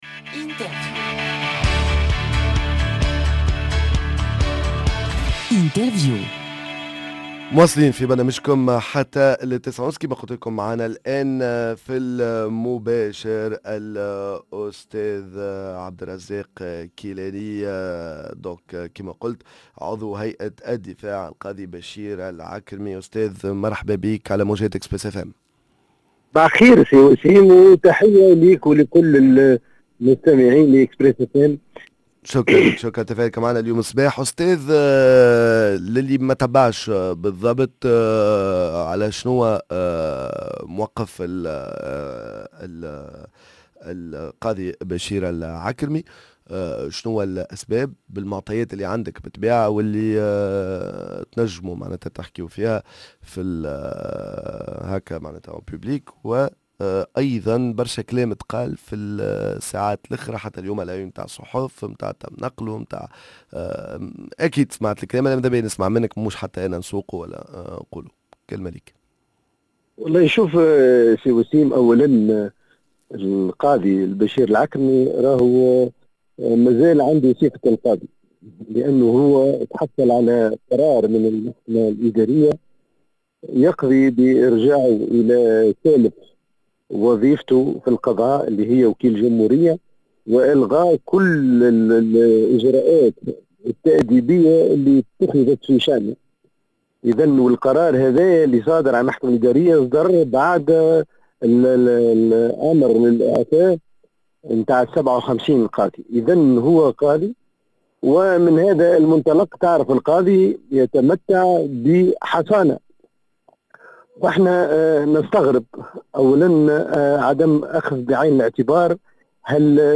L’interview عضو هيئة الدفاع عن القاضي بشير العكرمي الأستاذ عبد الرزاق الكيلاني تطورات ملف إيقاف القاضي بشير العكرمي